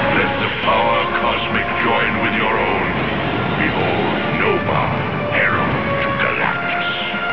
From the Fantastic Four animated series.